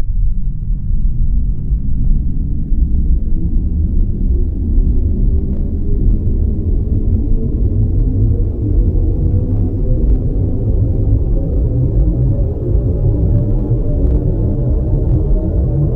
acceleration-old1.wav